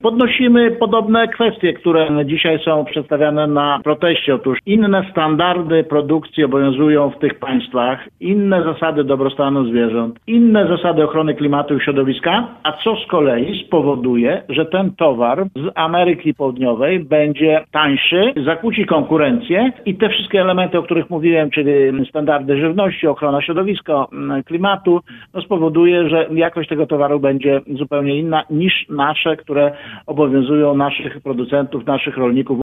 – Polskie rolnictwo może na tym stracić – mówi Polskiemu Radiu Lublin wiceminister rolnictwa i rozwoju wsi Jacek Czerniak (na zdj.).